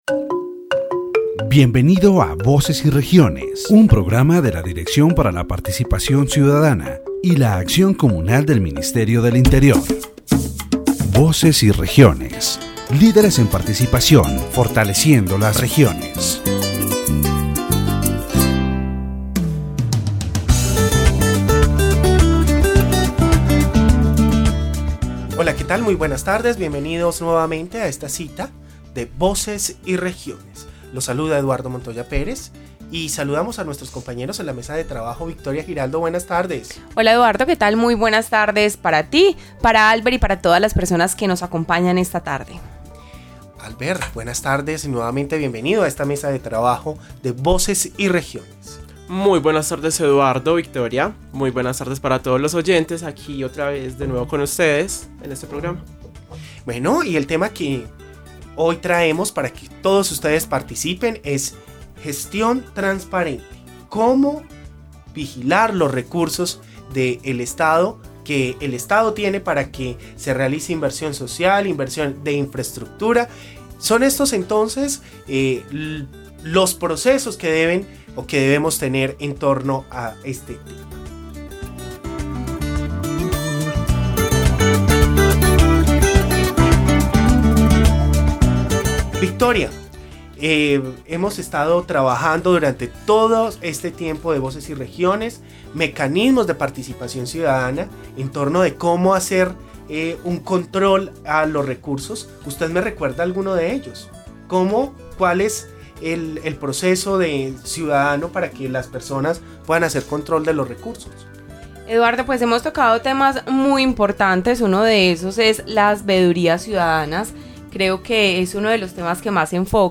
The radio program "Voices and Regions" of the Directorate for Citizen Participation and Community Action of the Ministry of the Interior focuses on the transparent management of public resources.